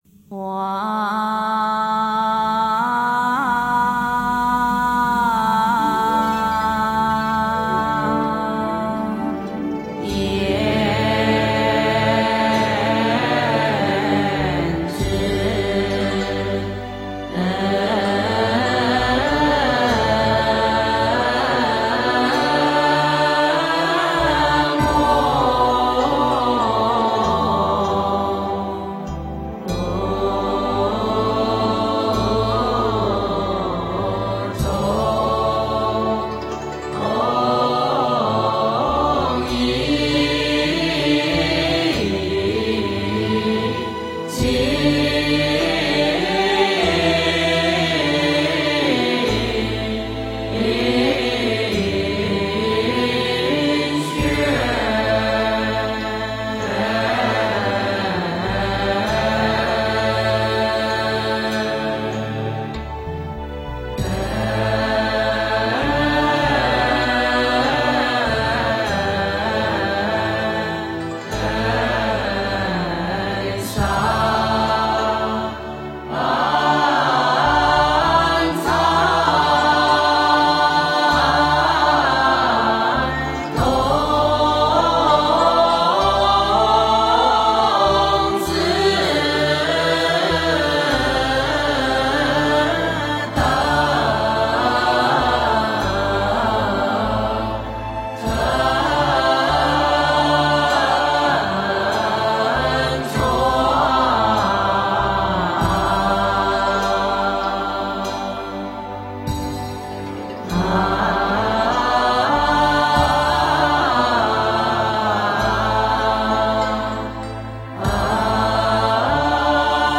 字母赞 诵经 字母赞--如是我闻 点我： 标签: 佛音 诵经 佛教音乐 返回列表 上一篇： 浴佛偈 下一篇： 大慈大悲观世音 相关文章 半若波罗蜜多心经 半若波罗蜜多心经--龚玥...